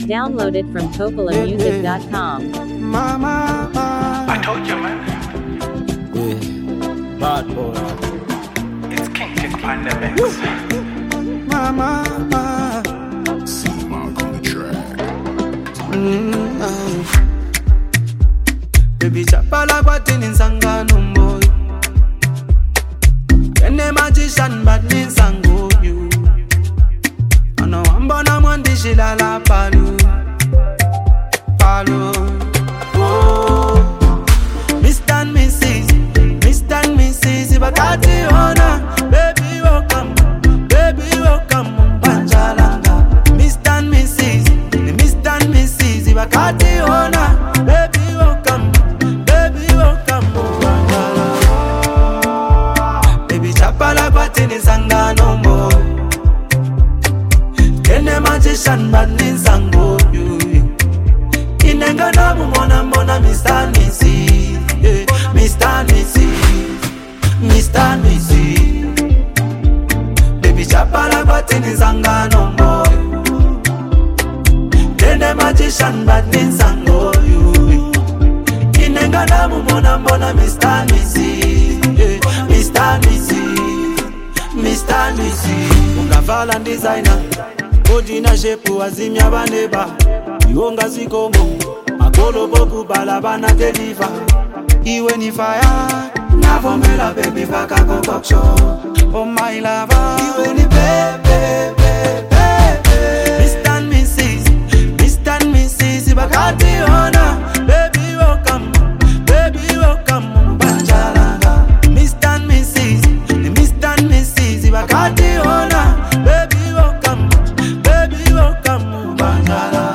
heartfelt and emotionally rich love song
soft, melodic instrumental